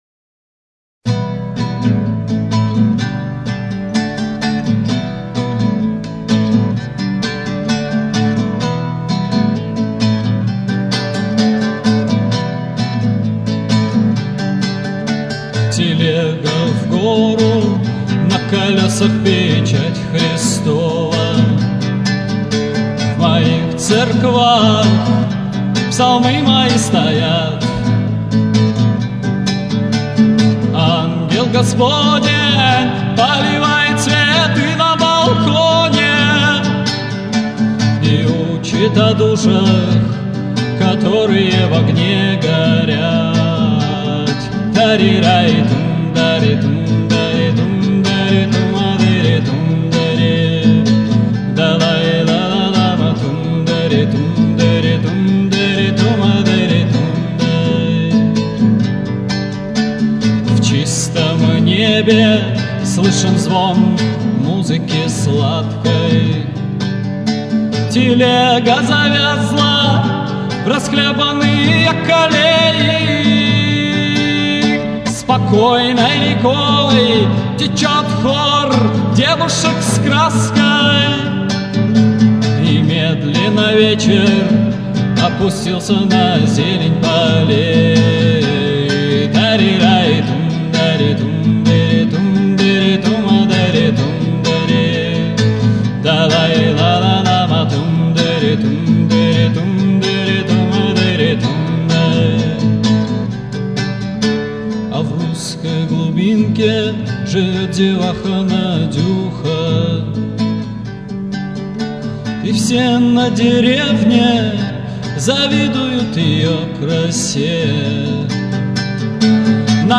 Демоальбом - г. Москва